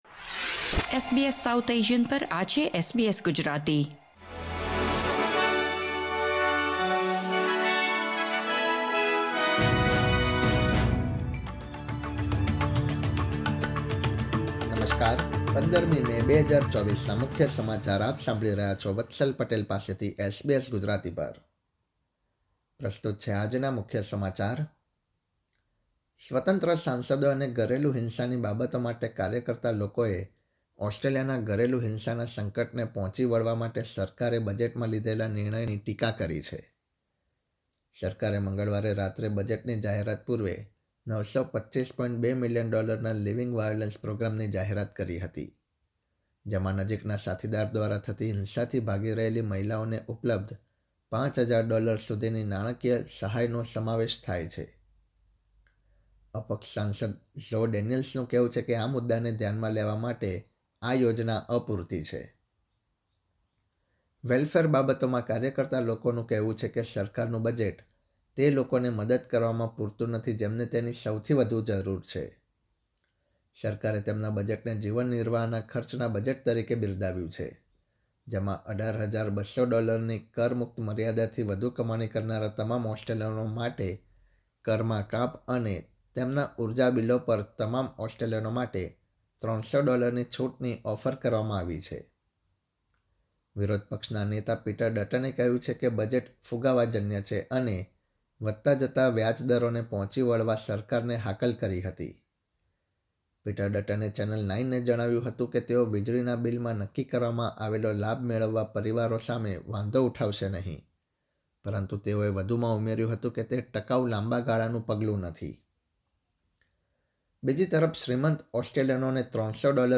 SBS Gujarati News Bulletin 15 May 2024